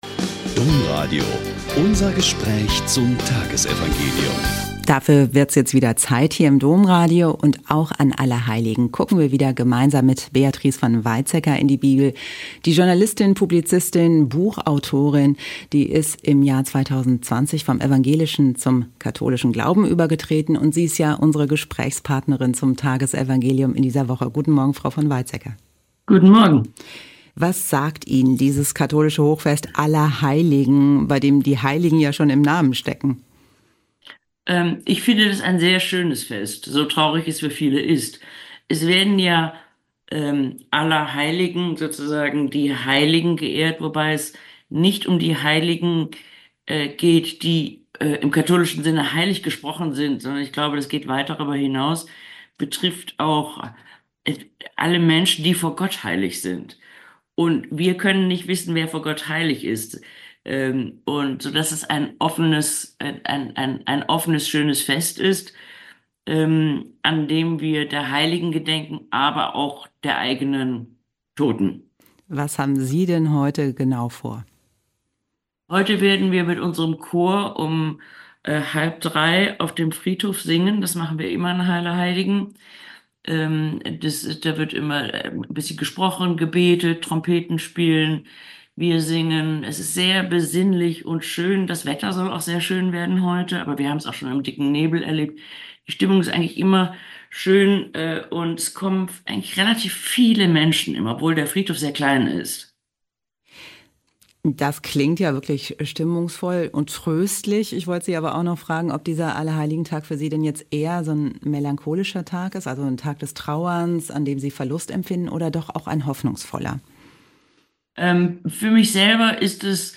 Mt 5,1-12a - Gespräch